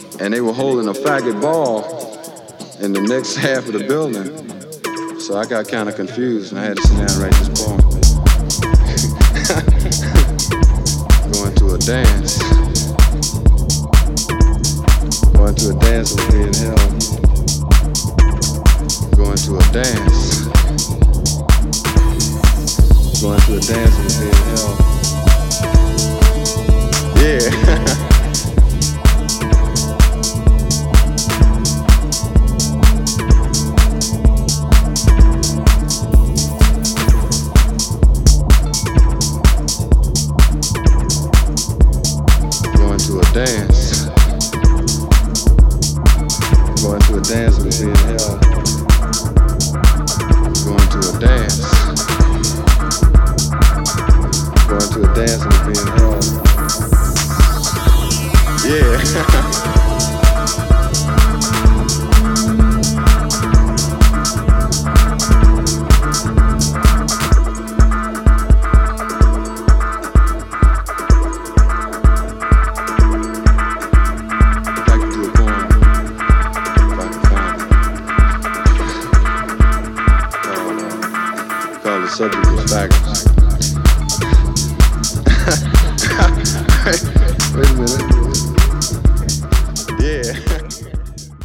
デトロイト方面のUSハウスからの影響色濃い一枚です。